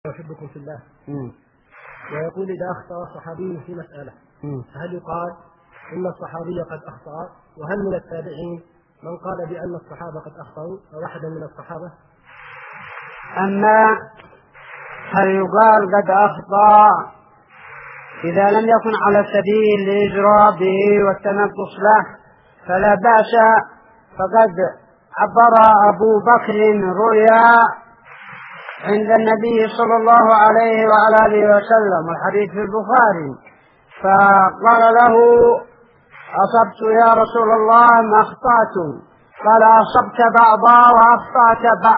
--------------- من شريط : ( محاضرة هاتفية لأهل السنة بالإمارات )